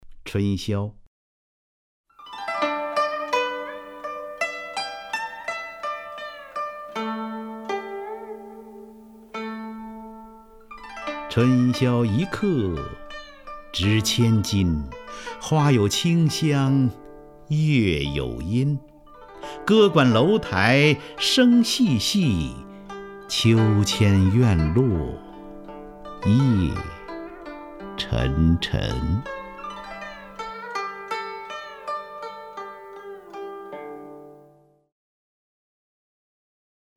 张家声朗诵：《春宵》(（北宋）苏轼) (右击另存下载) 春宵一刻值千金， 花有清香月有阴。